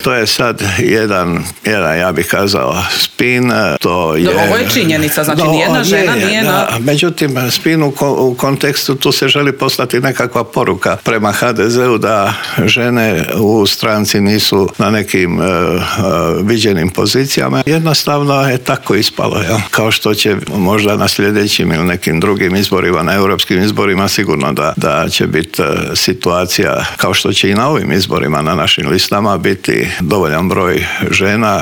ZAGREB - Koji je najizgledniji datum za parlamentarne izbore, kakva su očekivanja HDZ-a, kao nositelj liste u šestoj izbornoj jedinici želi li još jedan mandat na čelu MUP-a, odgovore smo u Intervjuu tjedna Media servisa potražili od potpredsjednika Vlade i ministra unutarnjih poslova Davora Božinovića, koji nam prokomentirao i Zakon o strancima, ali i kako stojimo s ilegalnim migracijama.